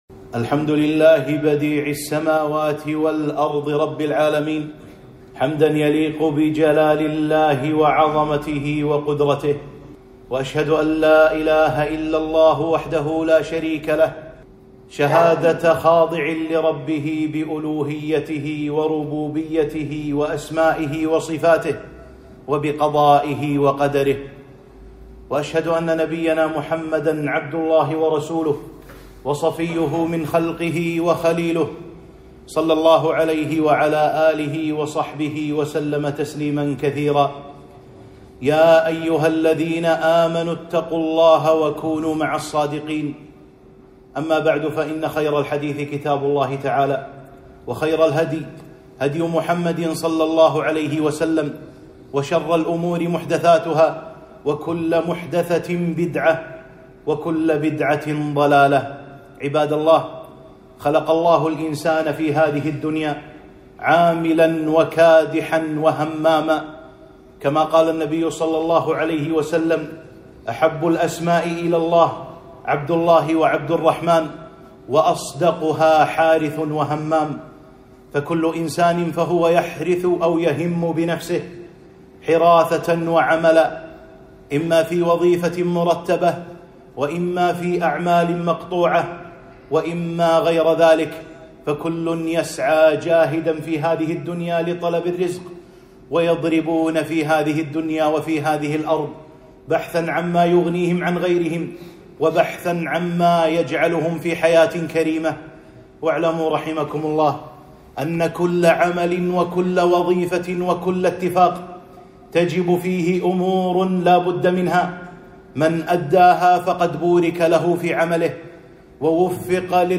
خطبة - إتقان العمل وأداء الأمانة